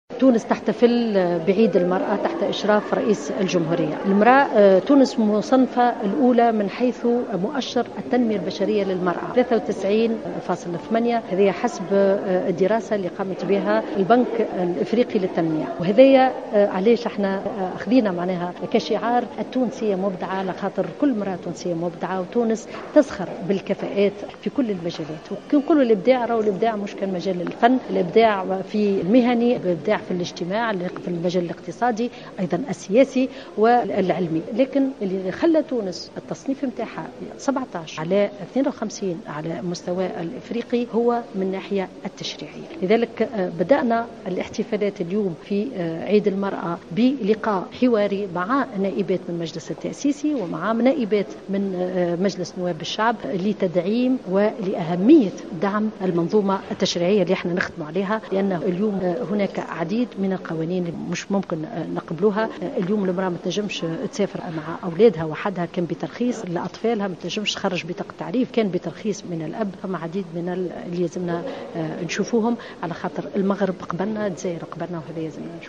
كشفت وزيرة المرأة والأسرة والطفولة سميرة مرعي في تصريح لمراسلة جوهرة أف أم...